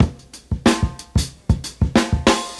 • 92 Bpm Breakbeat Sample E Key.wav
Free drum groove - kick tuned to the E note.
92-bpm-breakbeat-sample-e-key-jI9.wav